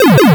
ChipTune Arcade FX 05.wav